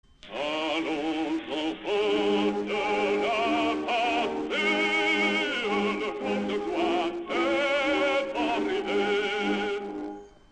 Звучит «Марсельеза». Кто поёт?
Правильный ответ: Фёдор Иванович Шаляпин